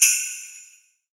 HJINGLEBEL2H.wav